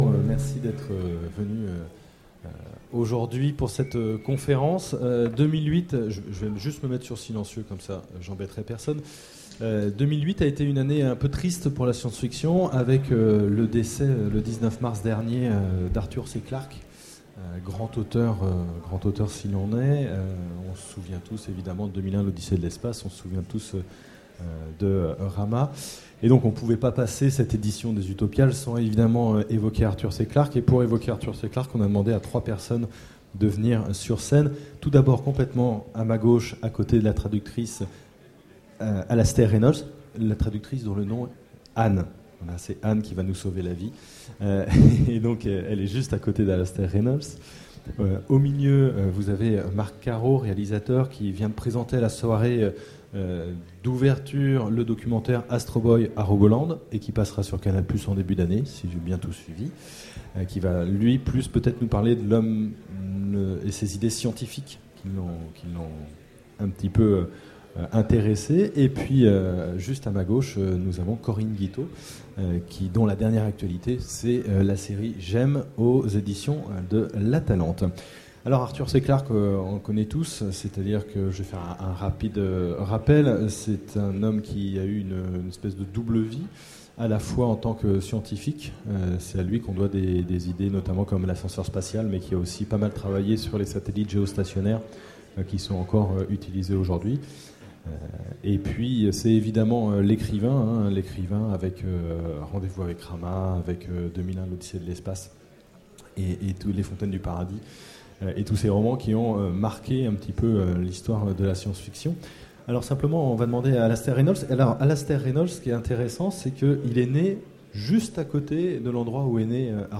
Utopiales 2008 : Conférence Hommage à Arthur C.Clarke
Voici l'enregistrement de la conférence Hommage à Arthur C.Clarke aux Utopiales 2008.